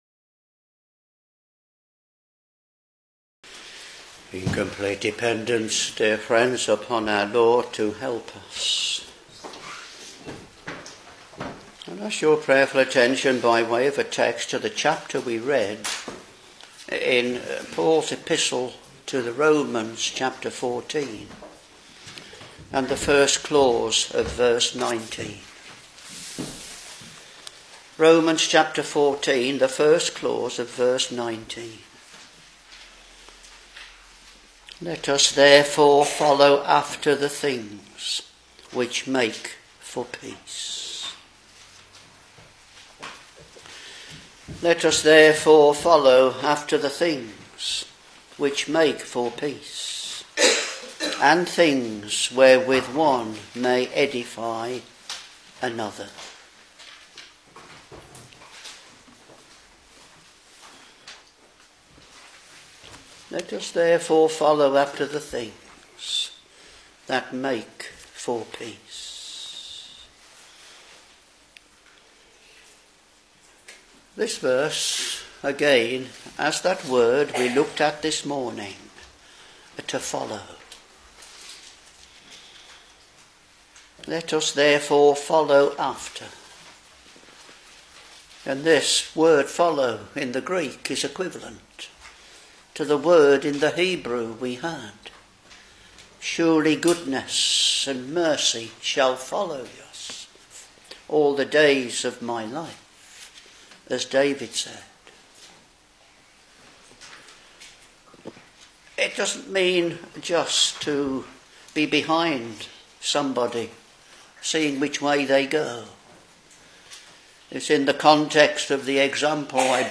Sermons Romans Ch.14 v.19 (first clause) Let us therefore follow after the things which make for peace